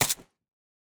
ar15_2.ogg